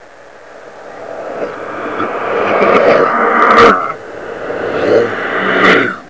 deadsignal4.wav